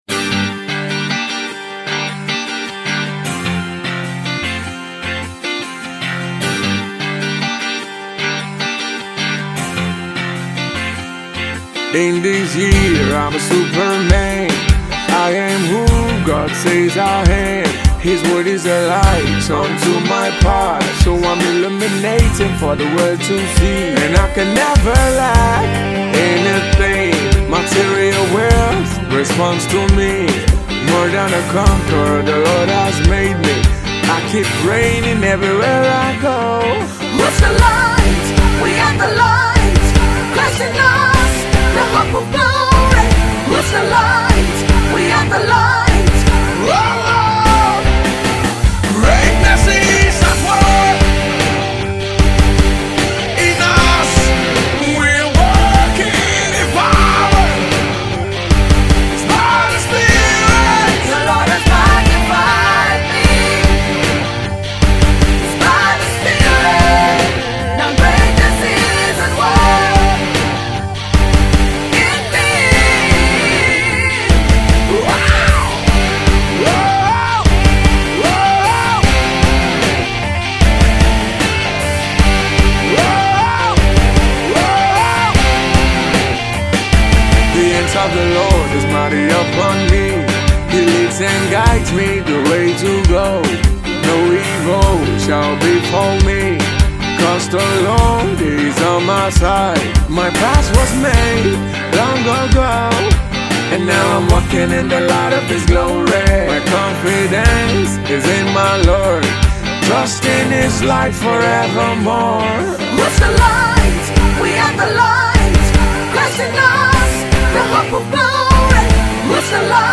rock hit single
a powerfull Rock flavored song.